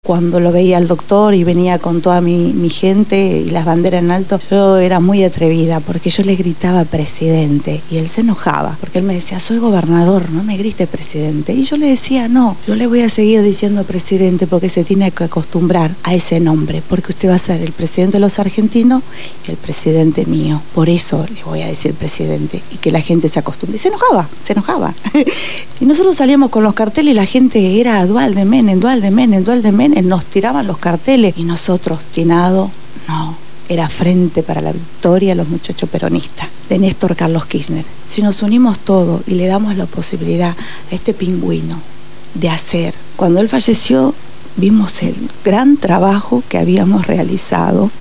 Estuvieron en los estudios de Radio Gráfica FM 89.3 durante el programa «Punto de partida» y nos conducieron por un relato desde las primeras internas que culminan llevándolo a la Intendencia de Río Gallegos, las anécdotas, las dificultades en la función de gobierno, sus sueños y las horas interminables de trabajo desde la visión de dos militantes de la primera hora.
En esta entrevista los describe y lo recuerda con emoción.